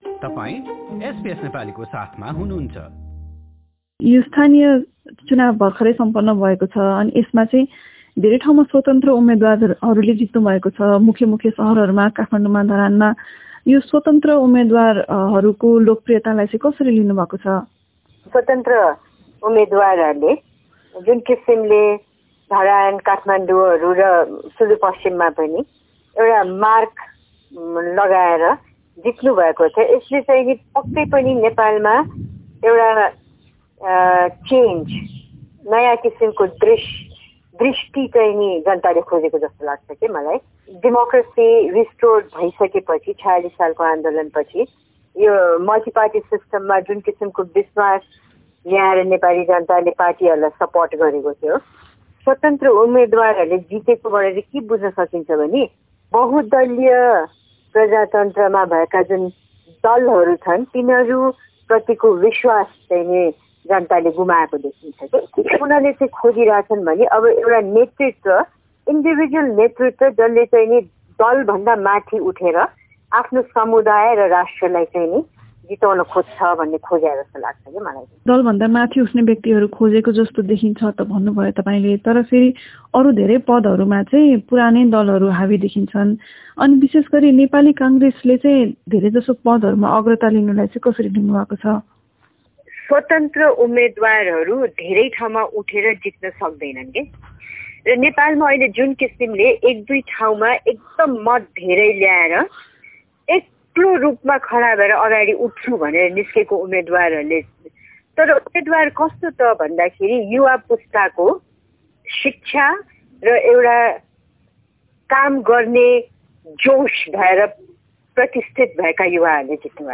गरिएको पूरा कुराकानी सुन्नुहोस्।